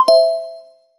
bright chime, positive tone, game UI sound
bright-chime-positive-ton-anpfsyv2.wav